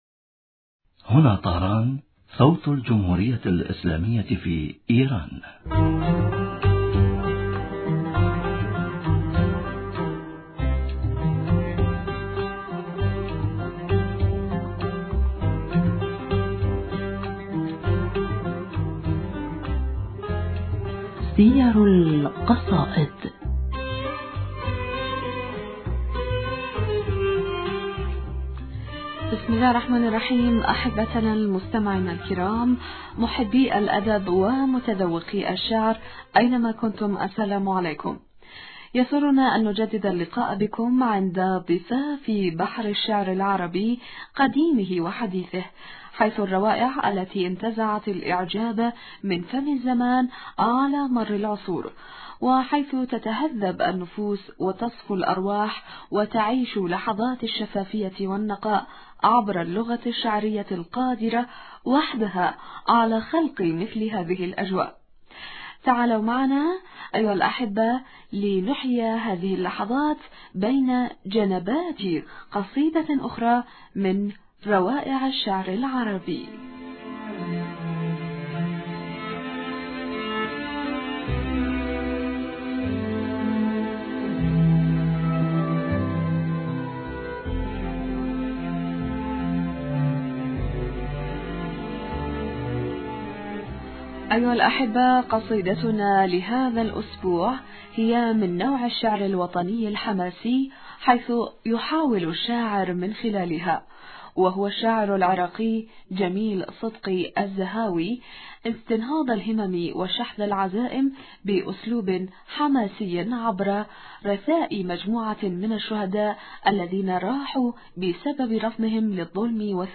معي في الستوديو ضيفي الكريم